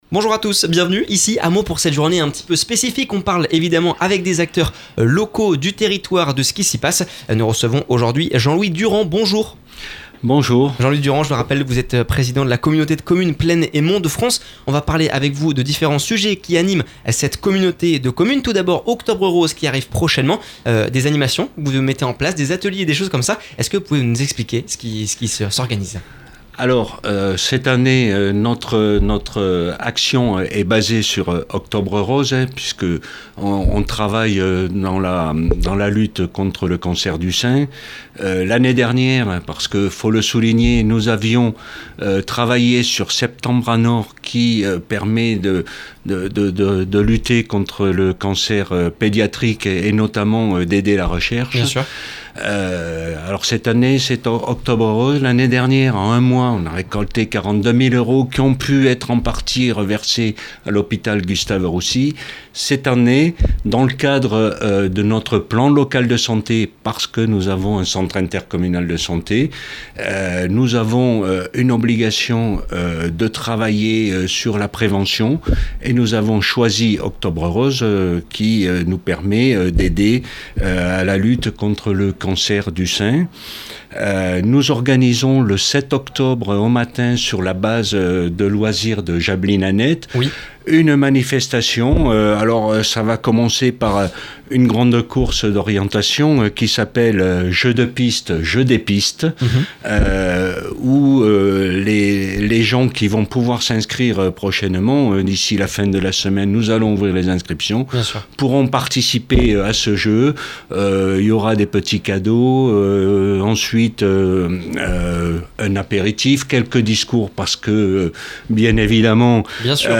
Les collectivités et acteurs locaux du département se sont donnés rendez-vous dans les locaux de Meaux pour une journée spéciale sur Oxygène. L’occasion pour Jean-Louis Durand, président de la communauté de communes Plaines et Monts de France d’évoquer les sujets majeurs de début de rentrée.